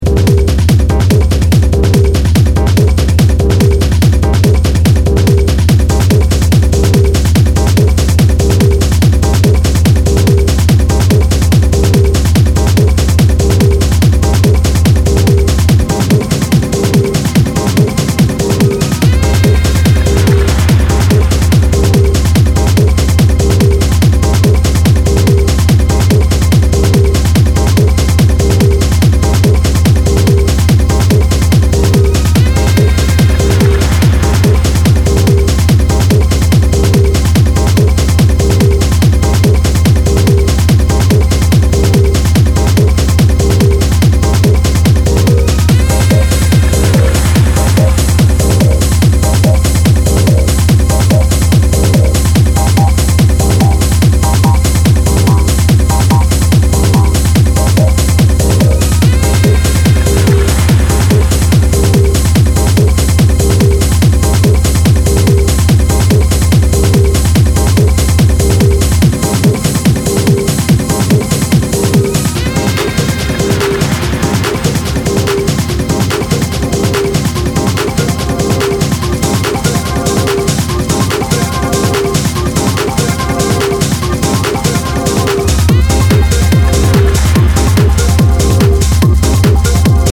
high-octane approach to techno